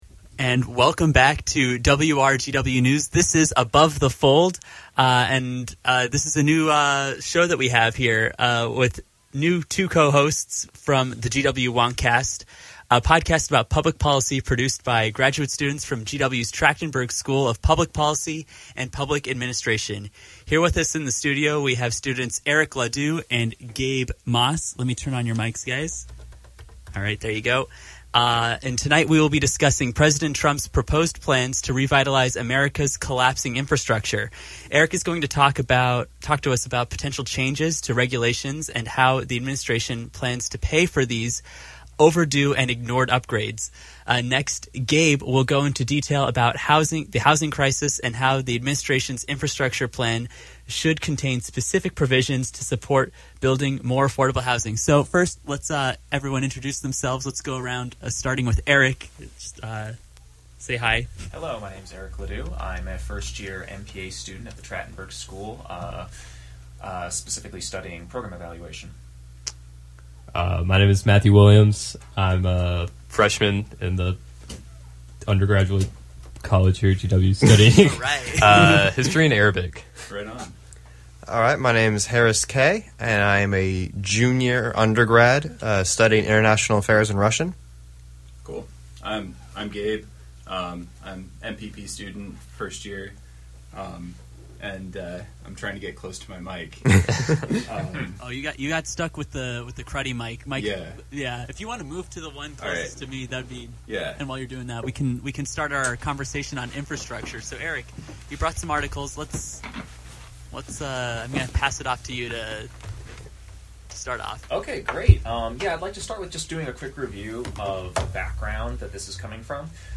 The GW WonkCast launches its first “Above the Fold” segment, recorded live with GW’s radio station WRGW.